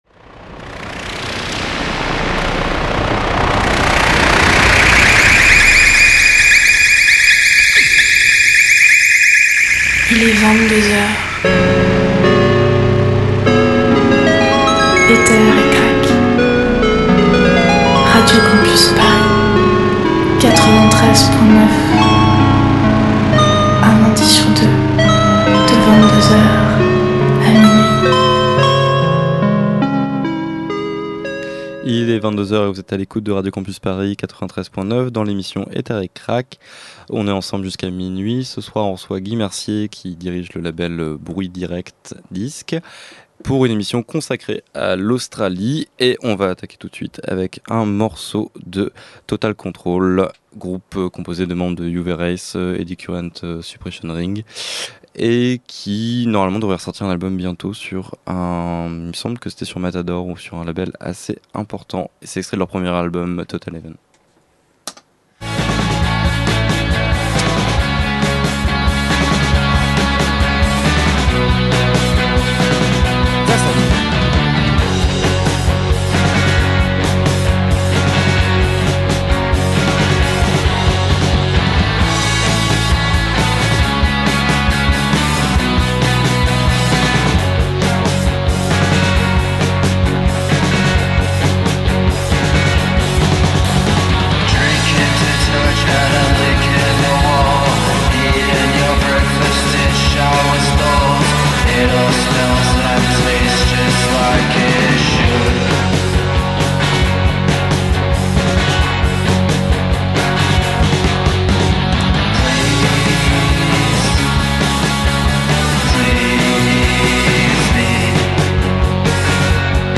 garage/punk/expé